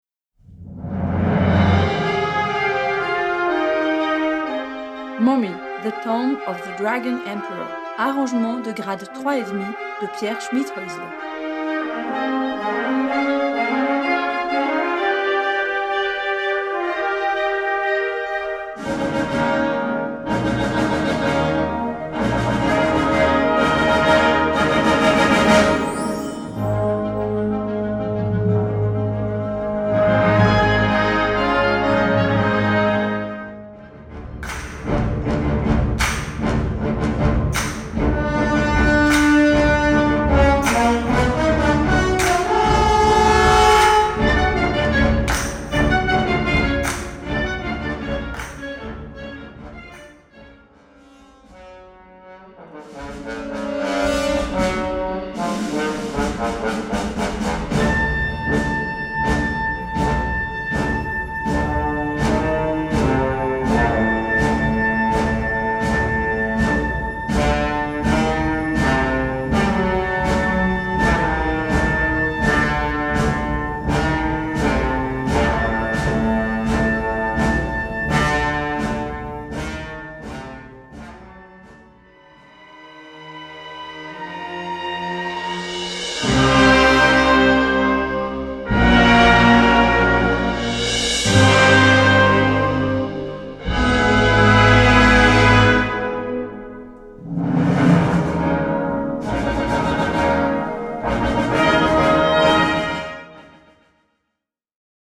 Wind Band (harmonie)
Easy Listening / Unterhaltung / Variété